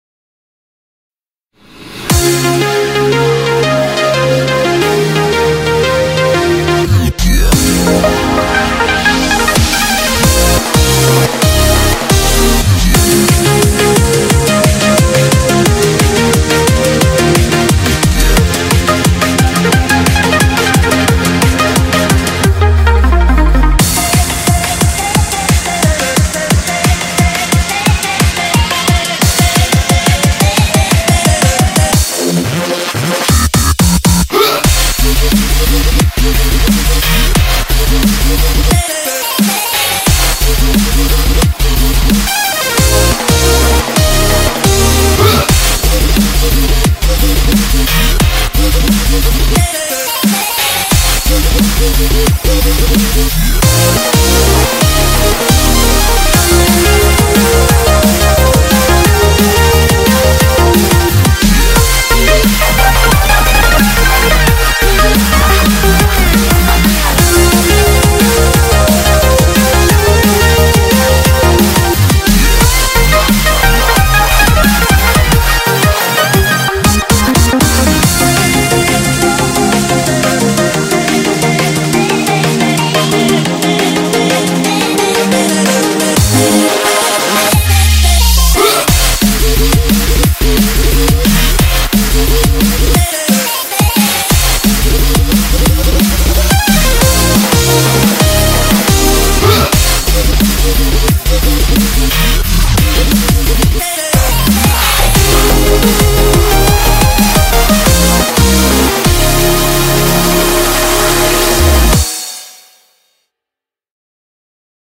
BPM177
Audio QualityPerfect (Low Quality)